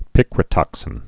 (pĭkrə-tŏksĭn)